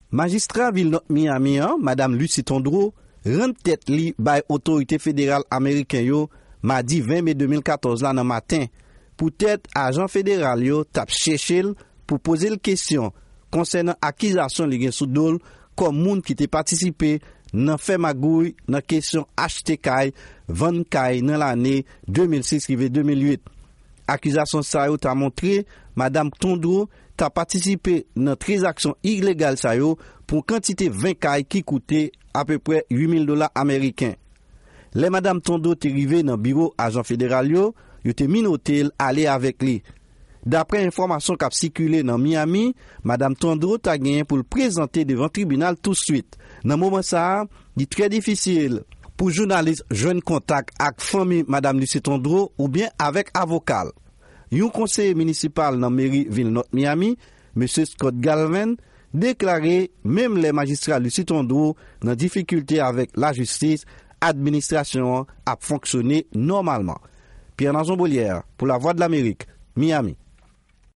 Repòtaj